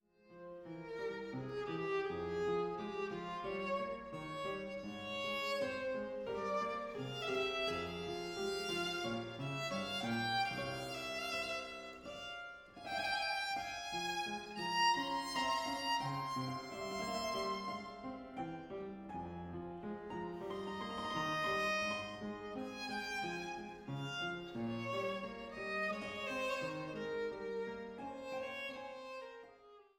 Silbermannflügel & Cembalo
Violine